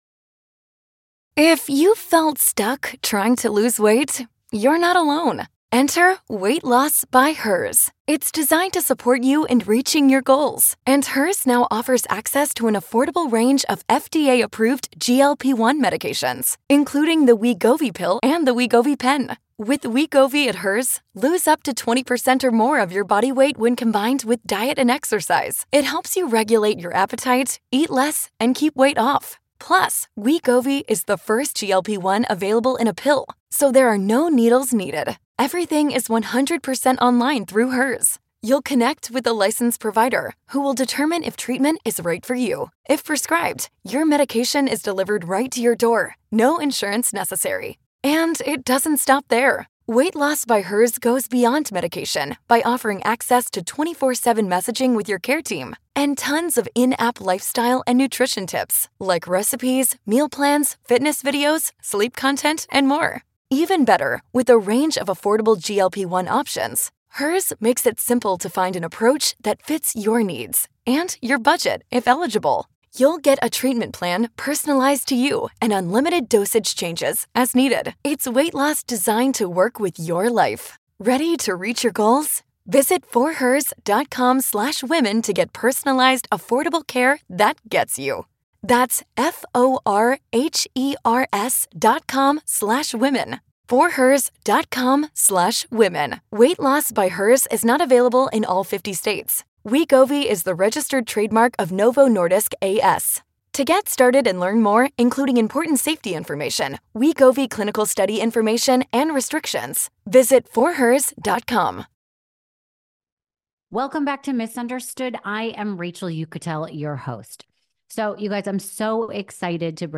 Get ready for an emotional conversation that will inspire you to help make a difference!